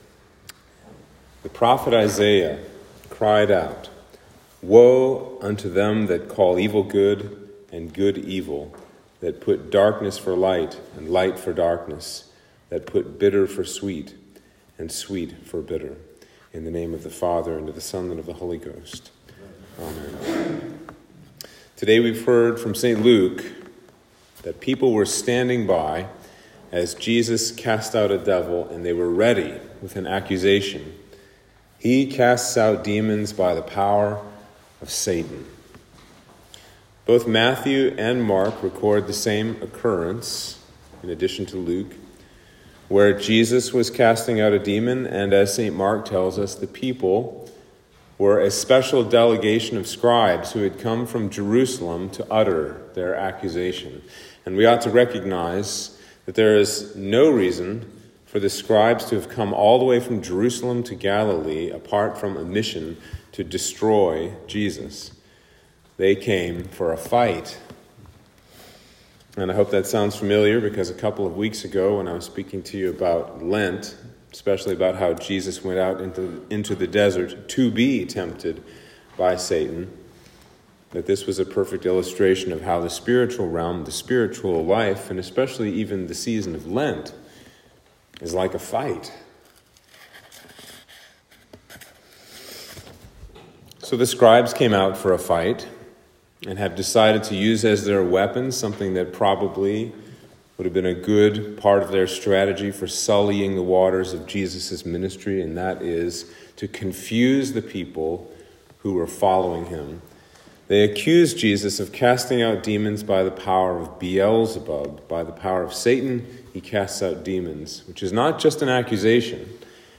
Sermon for Lent 3